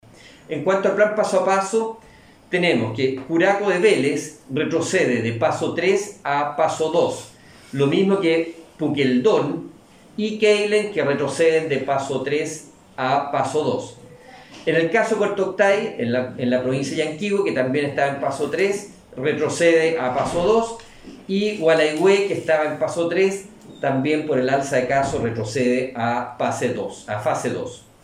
De esta manera lo comunicó el seremi de Salud, Alejandro Caroca.